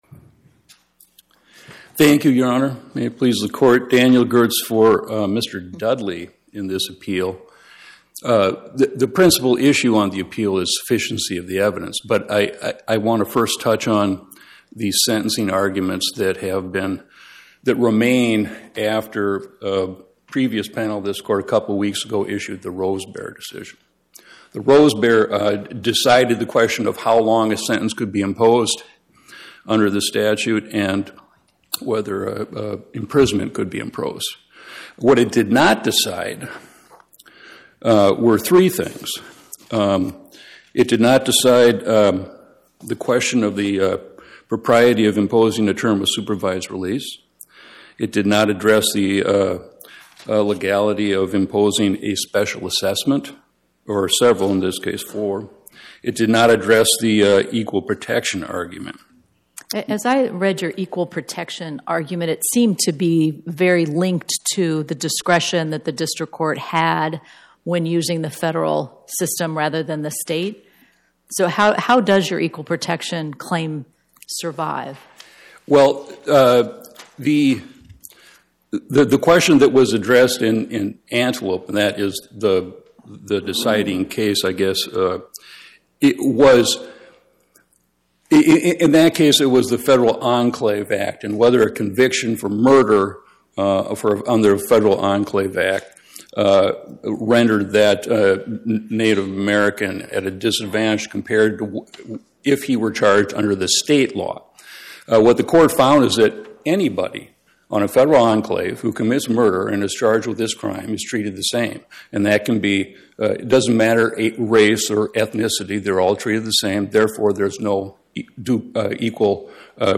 Oral argument argued before the Eighth Circuit U.S. Court of Appeals on or about 02/12/2026